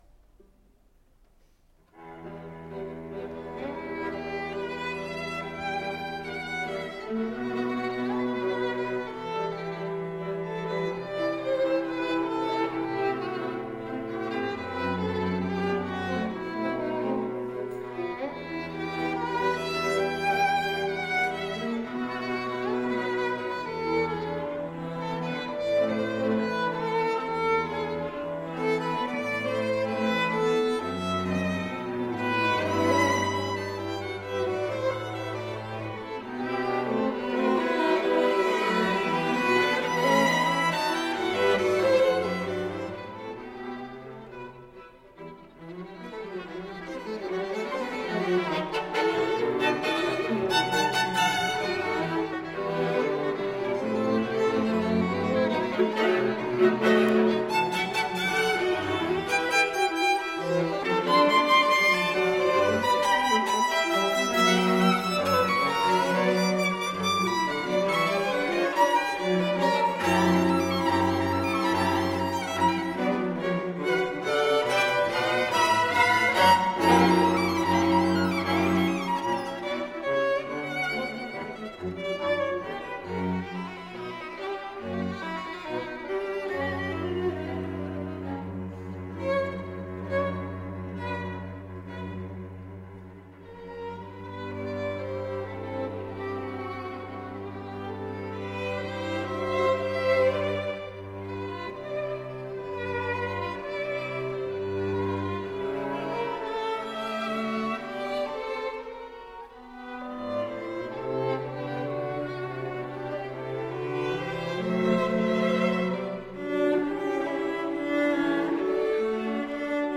Emerging Artists Concert recordings - July 19, 2016 - afternoon
String Quartet in E minor, Op. 44, no. 2
violin
cello